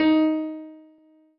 piano-ff-43.wav